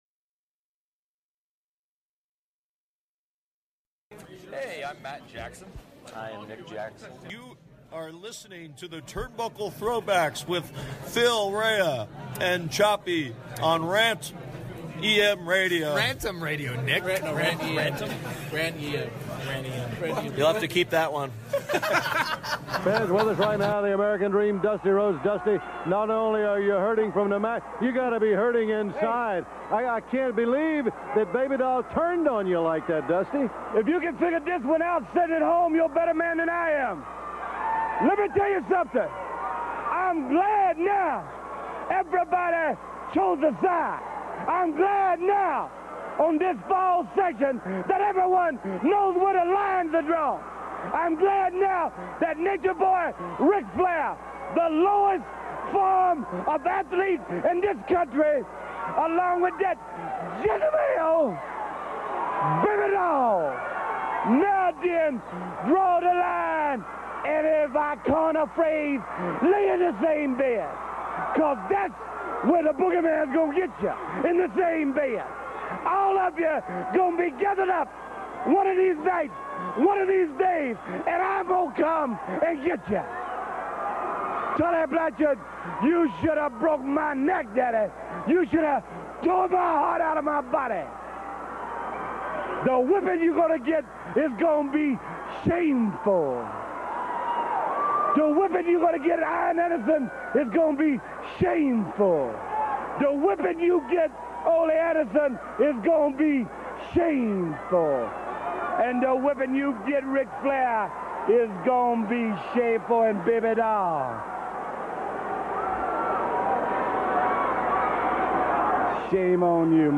does a quick solo show